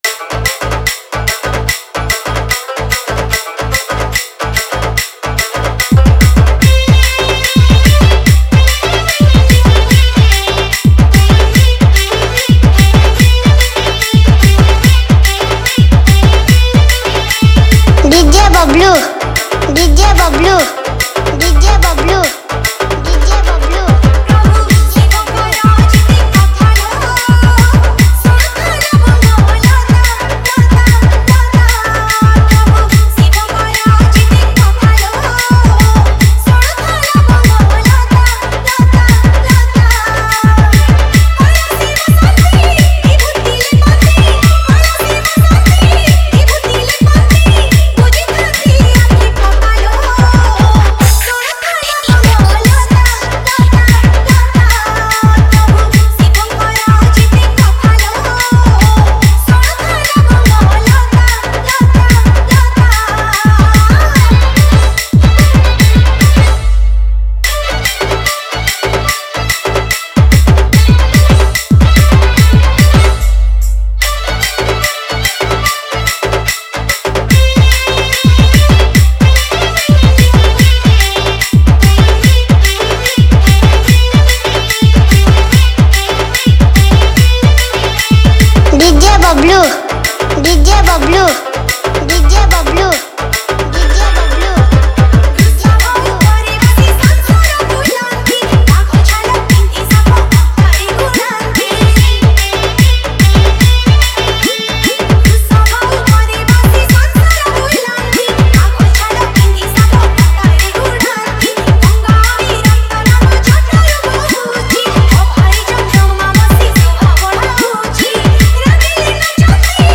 Category:  Odia Bhajan Dj 2019